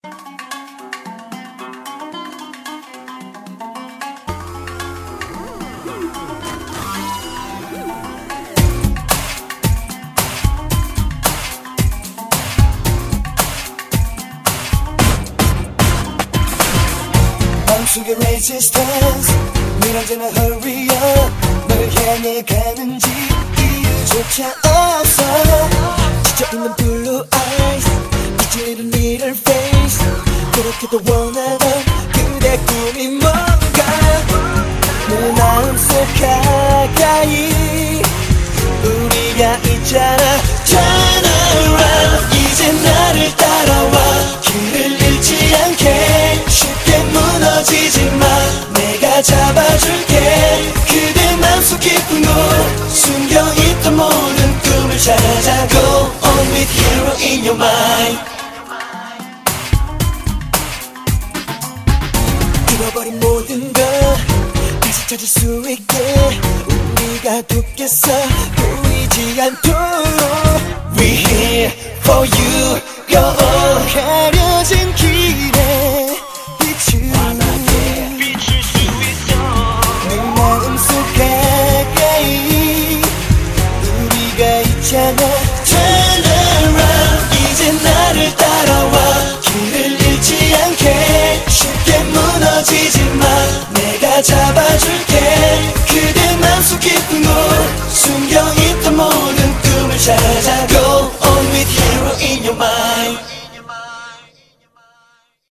BPM112--1
Audio QualityPerfect (High Quality)